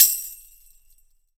TAMB DUAL-2.wav